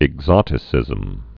(ĭg-zŏtĭ-sĭzəm)